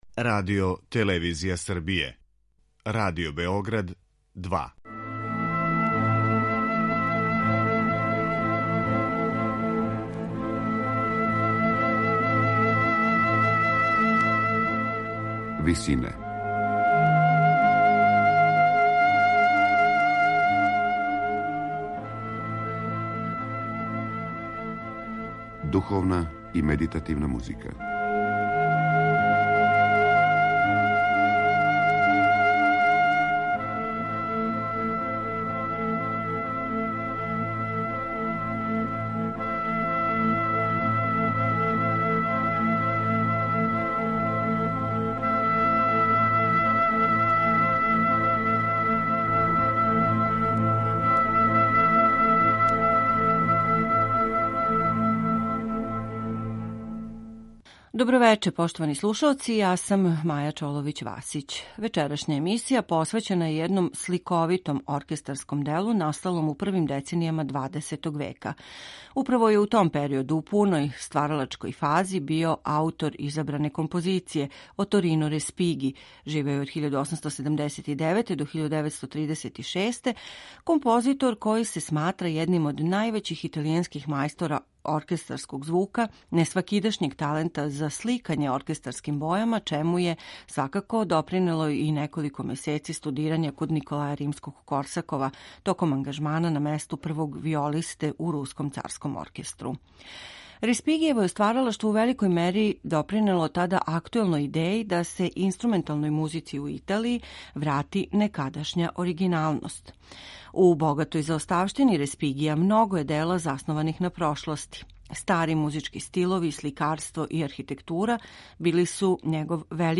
четири симфонијске поеме
засноване на грегоријаснком коралу